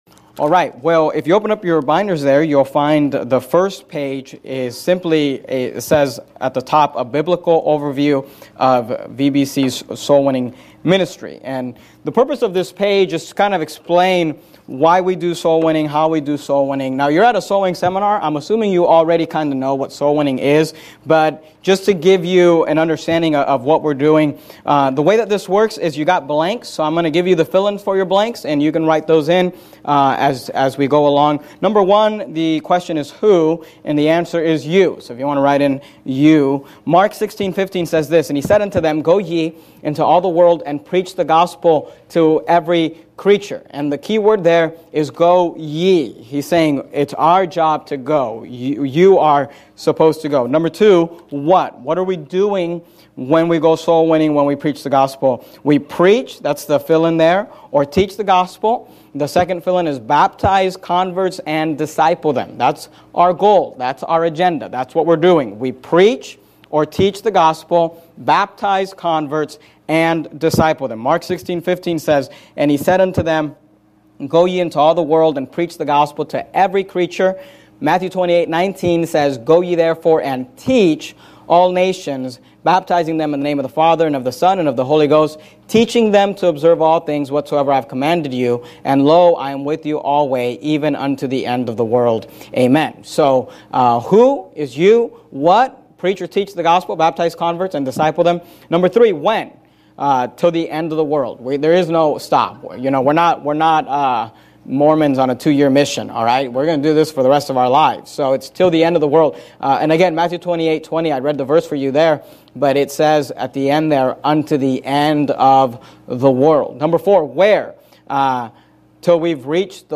Soulwinning Seminar